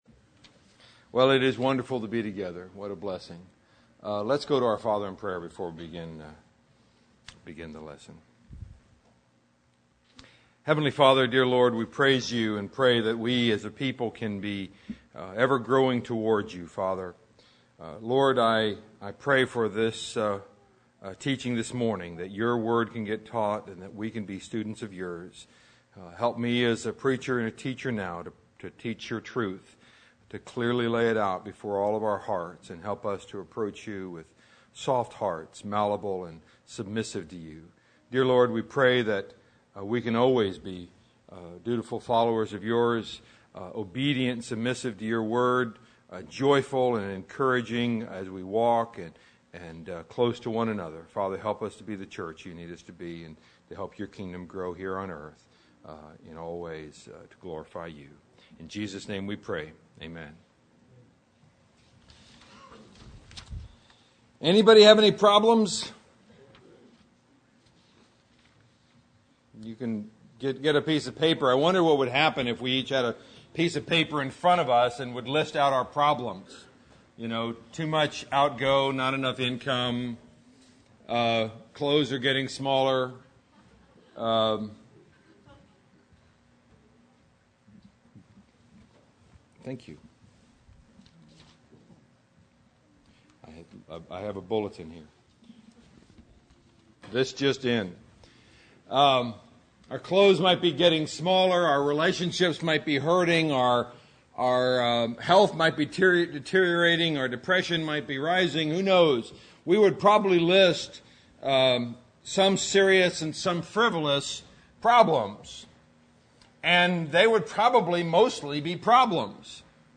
Valley church of Christ - Matanuska-Susitna Valley Alaska
Audio Sermons